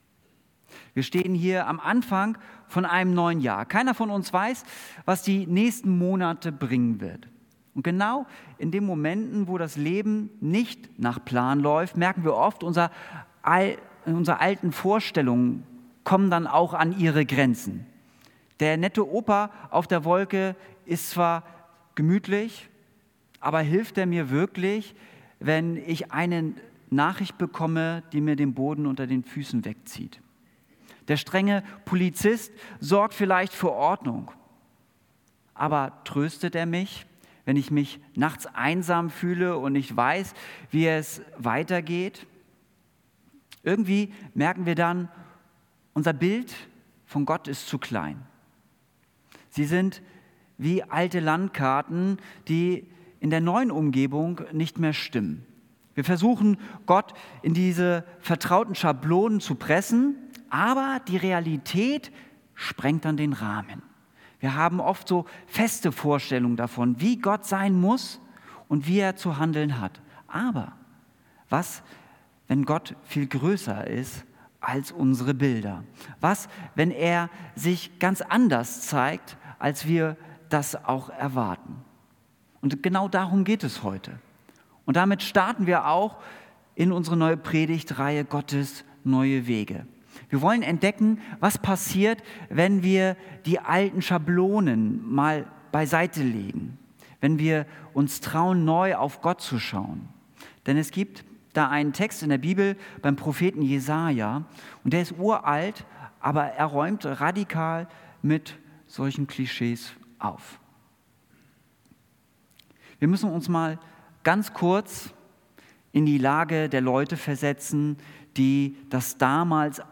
Jesaja 42,14 -16 – Aus technischen Gründen, fehlen die ersten vier Minuten.
Predigt